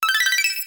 finish_success.wav